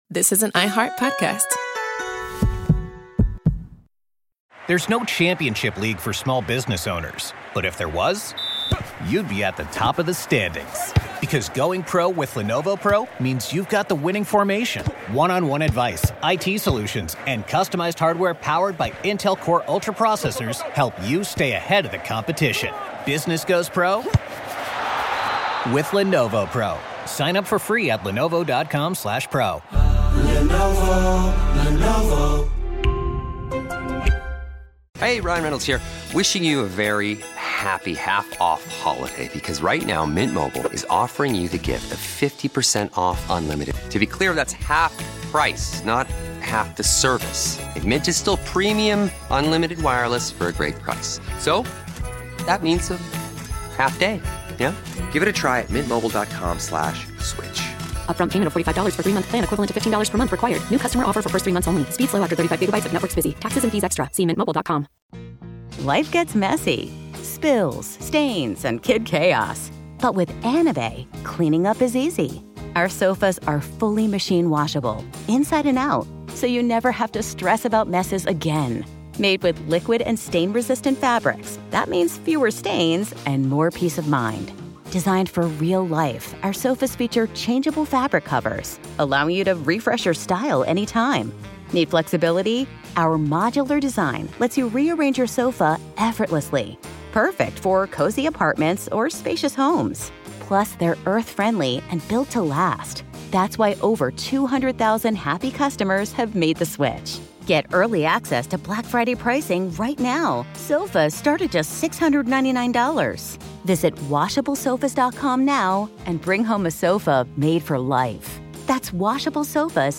Each call traces the shape of faith, regret, and forgiveness inside a place built for punishment.